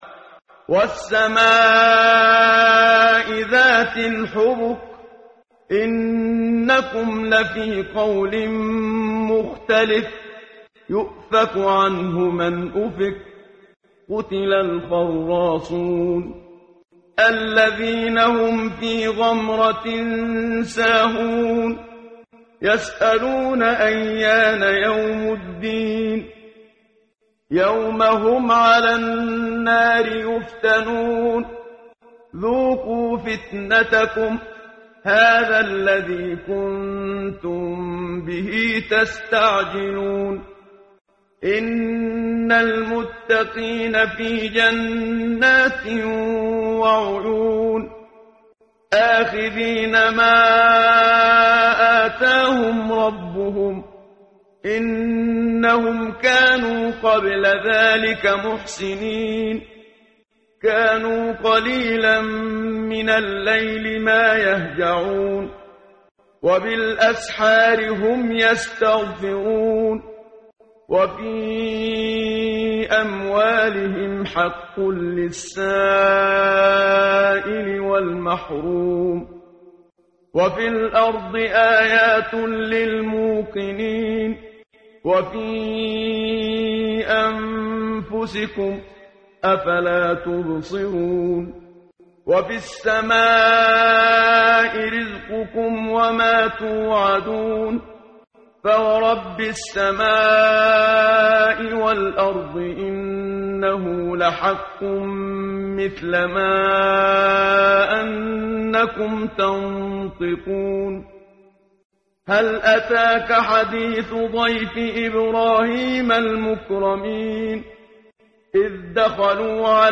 قرائت قرآن کریم ، صفحه 521 ، سوره مبارکه « الذاریات» آیه 7 تا 30 با صدای استاد صدیق منشاوی.